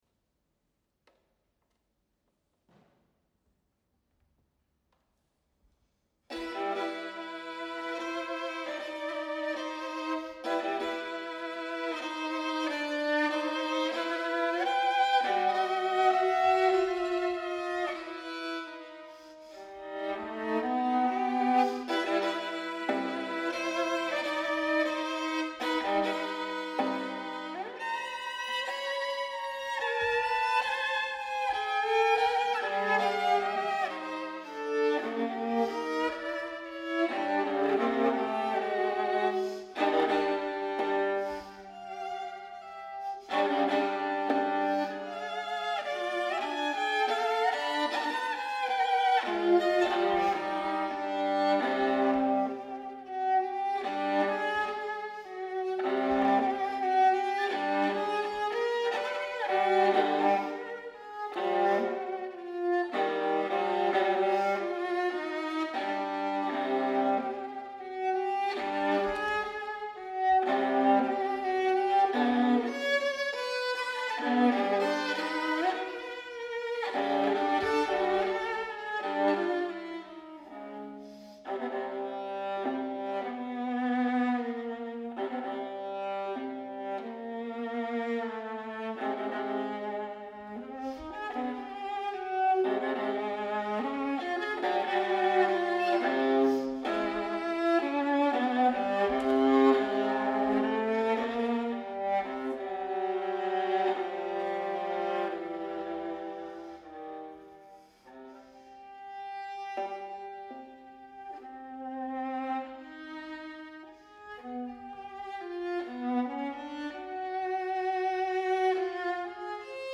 Venue: St. Brendan’s Church